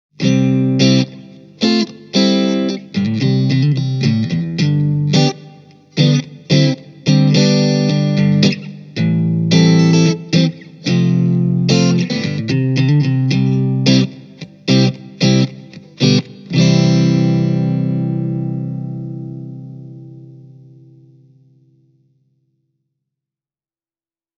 To give you an idea of how well the Red Box 5’s sound stands up to a physical microphone, I have recorded a few clips simultaneously using a Shure SM57 and the Red Box. I used a Blackstar HT-1R running into an Änäkäinen Rumble speaker.
Here’s a clean example, close-miked with the Shure: